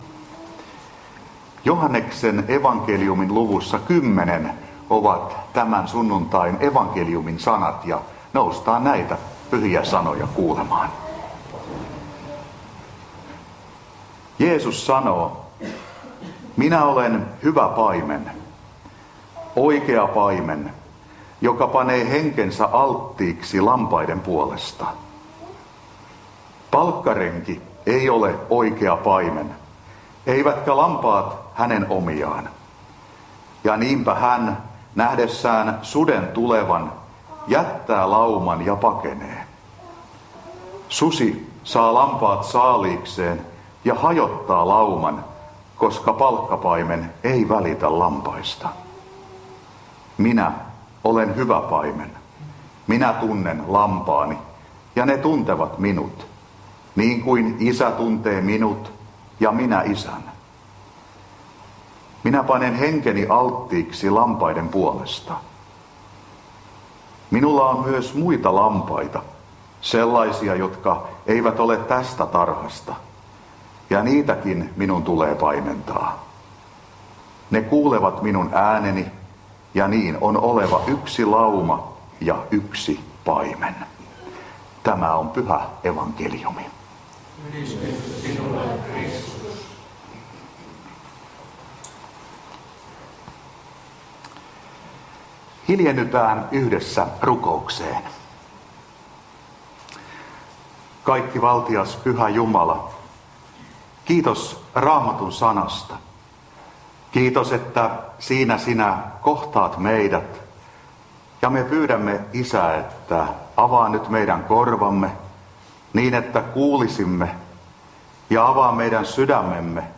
Sastamala